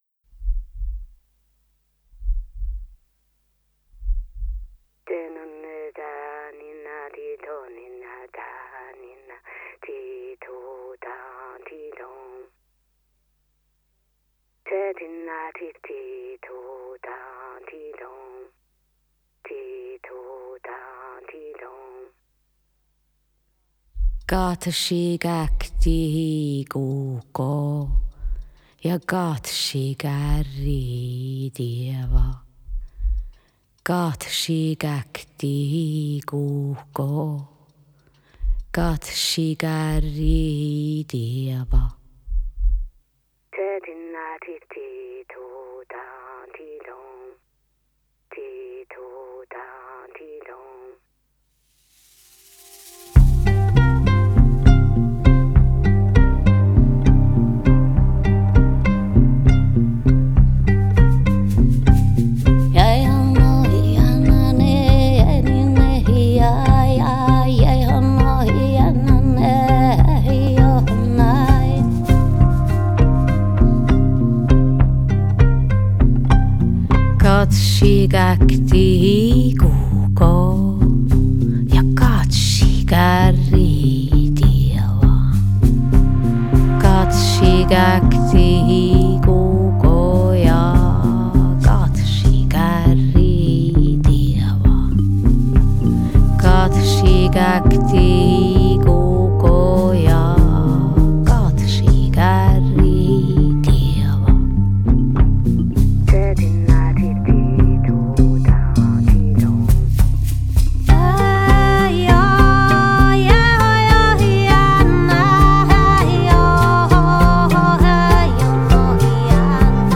Style: Saami folk, Joik
Stereo